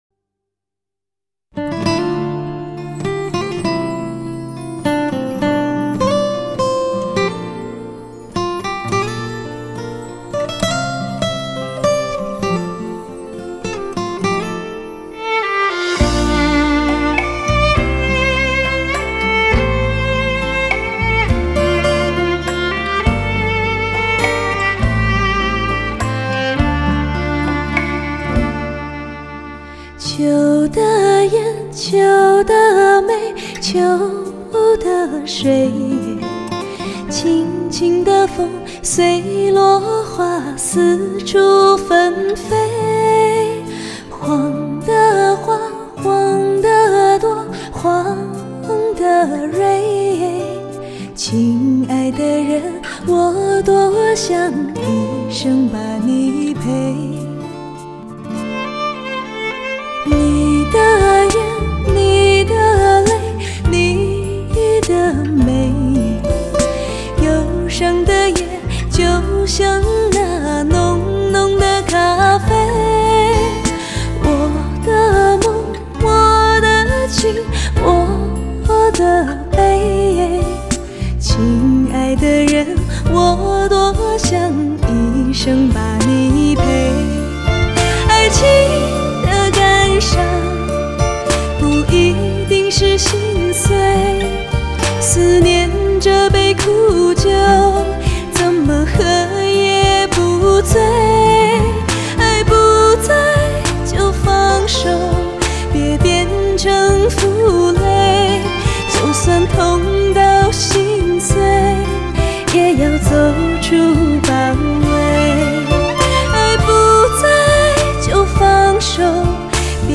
唱片类型：汽车音乐